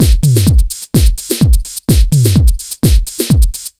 127BEAT8 3-R.wav